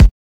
Wu-RZA-Kick 49.wav